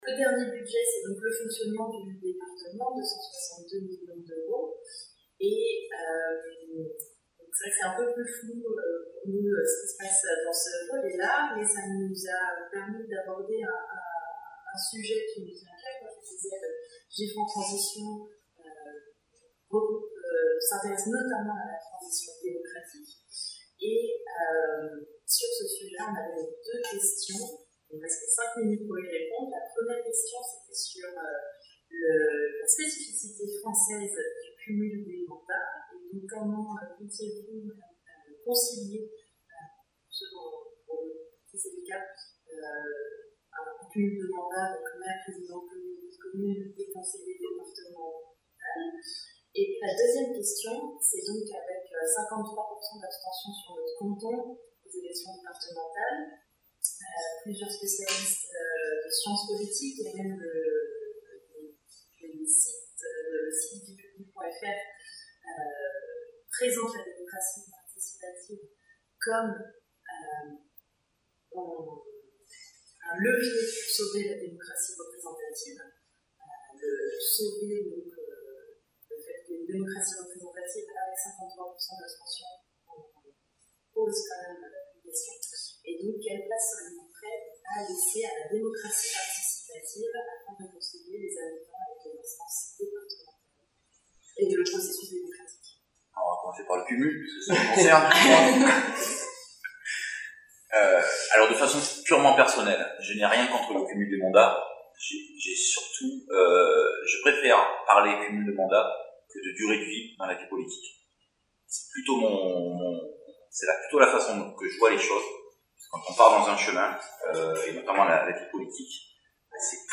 les importantes précipitations bruyantes et un problème technique nous ont fait perdre en qualité d’enregistrement, nous le regrettons sincèrement et espérons que cela ne troublera pas trop votre écoute de leurs propos.